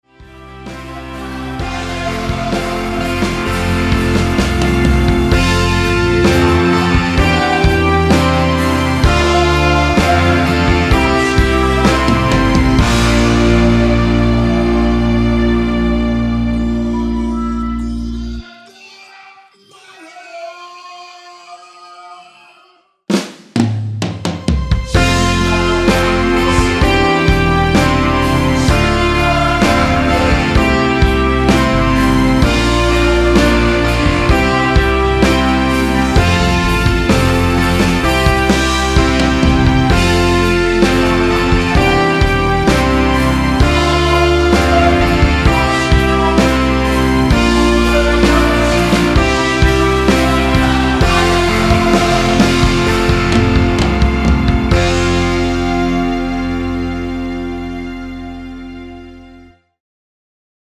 원키에서(-2)내린 코러스 포함된 MR입니다.
앞부분30초, 뒷부분30초씩 편집해서 올려 드리고 있습니다.
중간에 음이 끈어지고 다시 나오는 이유는